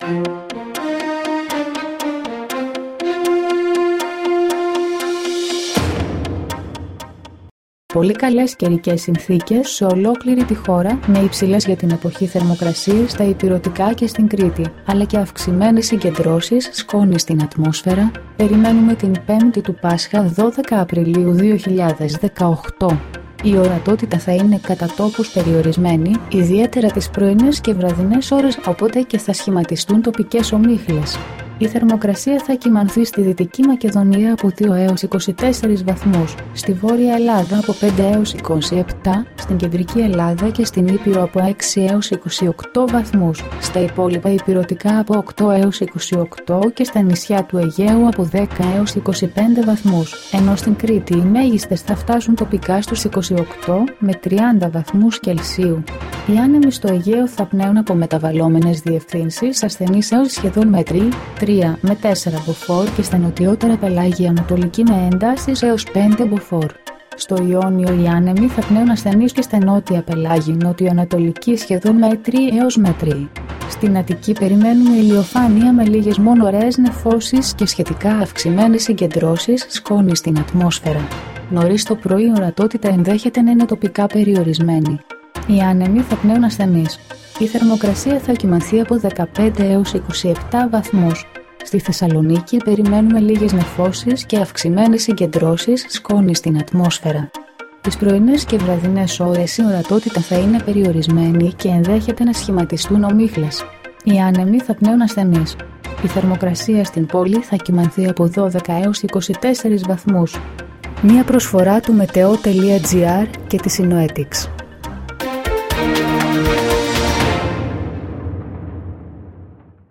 dailyforecastaaaaa.mp3